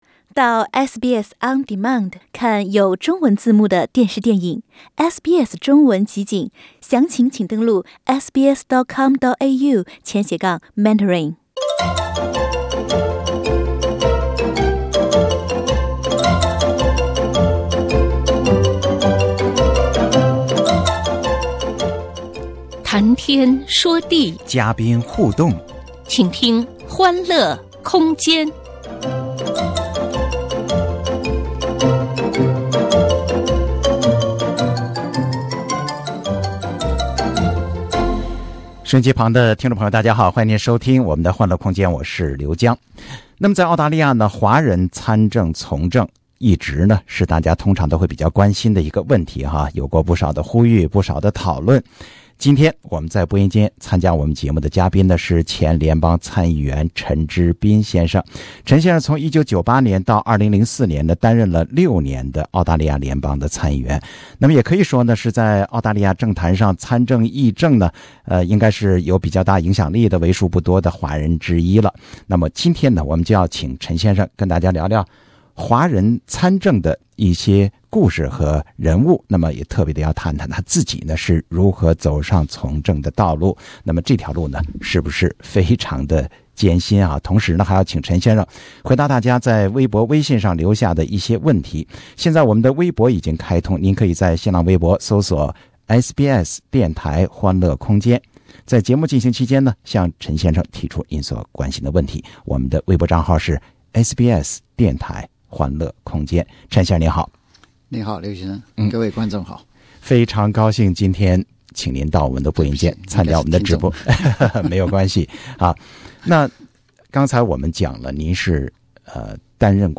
在2019年即将划上句号之际，让我们一起来回顾这段采访。